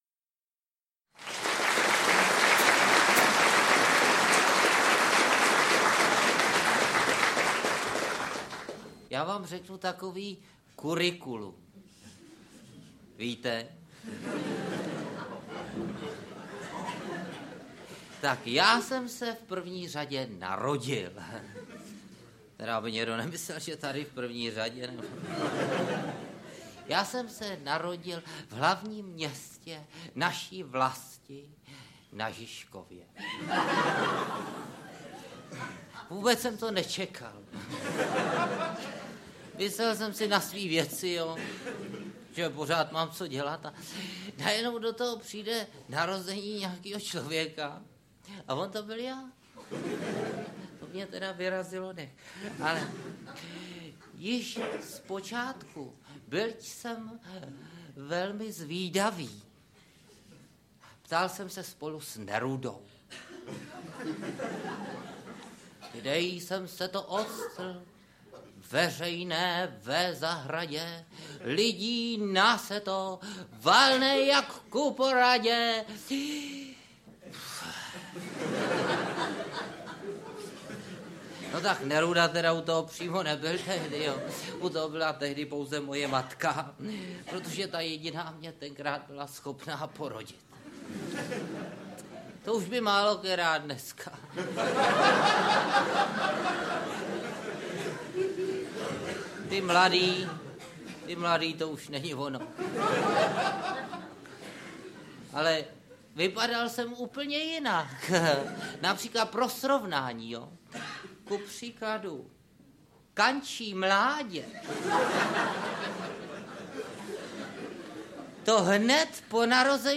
Audiokniha Návštěvní den č. 6 - obsahuje vybrané skeče ze stejnojmenného představení. Účinkují Petr Nárožný, Luděk Sobota, Miloslav Šimek.